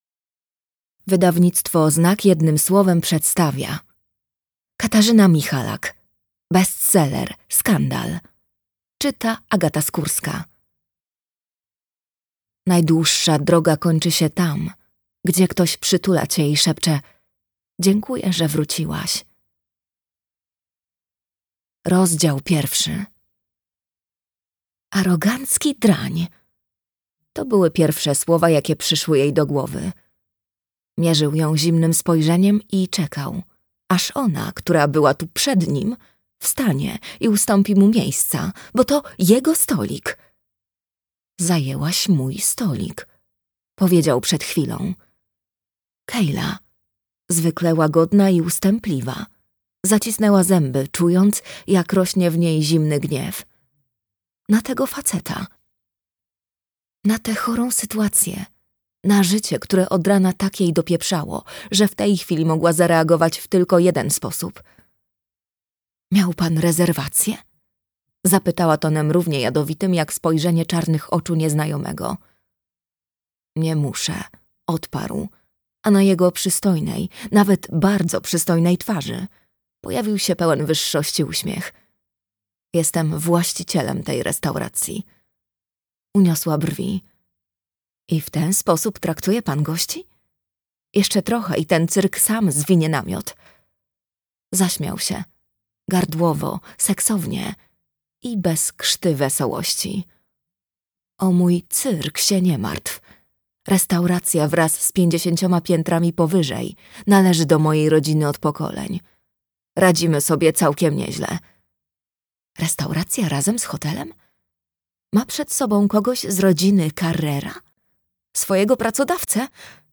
Skandal - Katarzyna Michalak - audiobook + książka - Legimi online